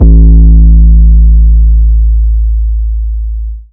808 Kick 10_DN.wav